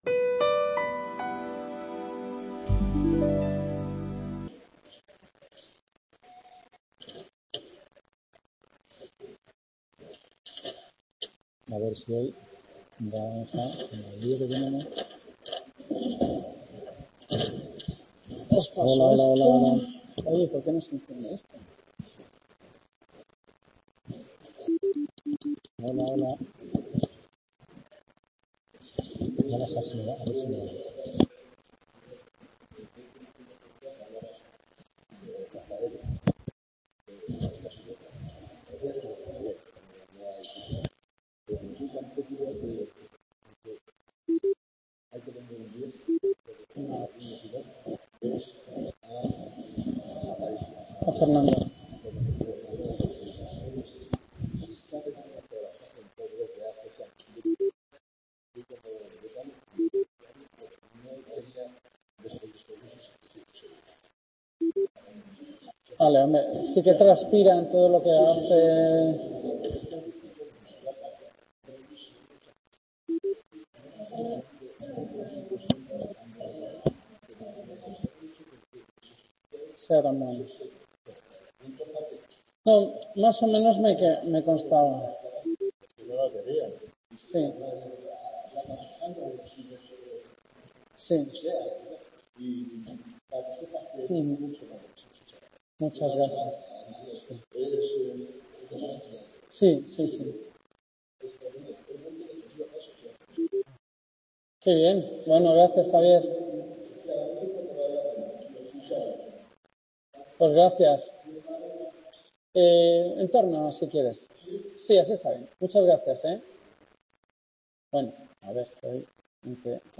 Tema 7 última clase | Repositorio Digital